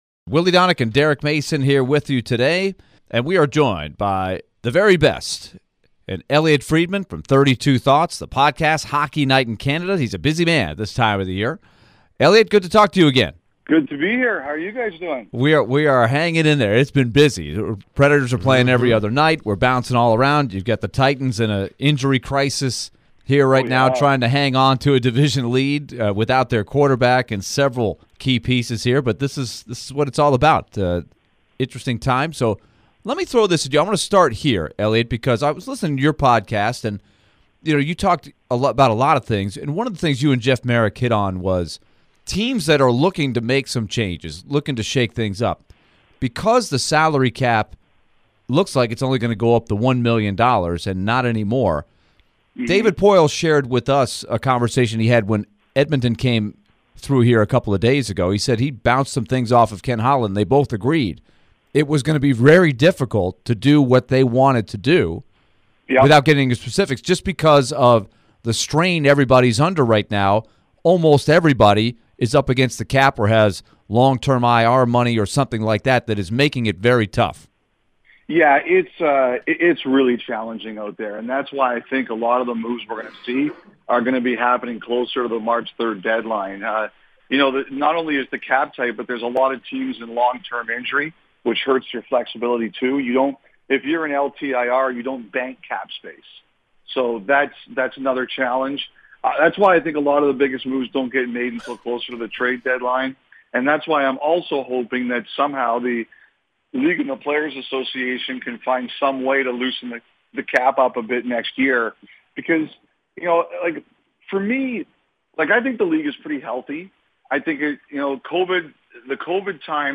Elliotte Friedman Interview (12-22-22)